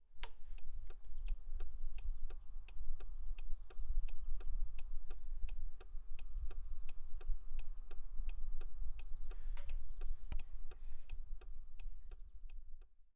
01 牵引器